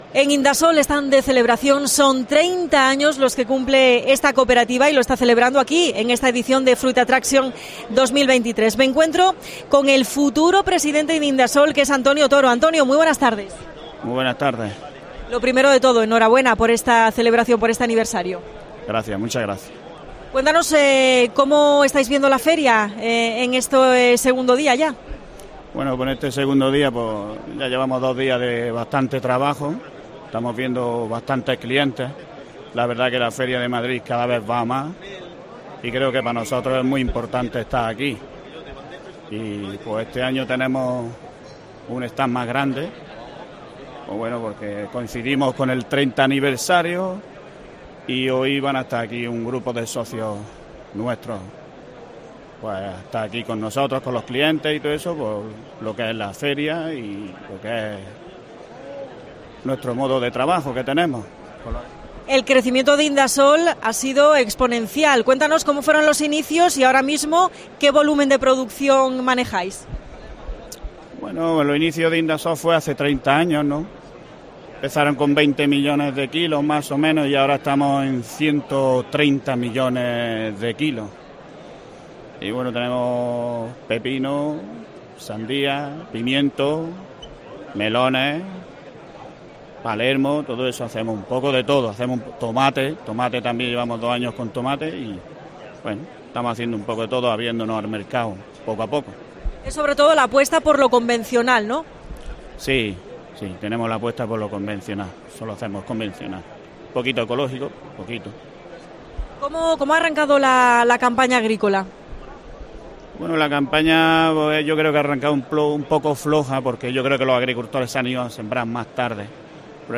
AUDIO: Especial desde Fruit Attraction en COPE Almería.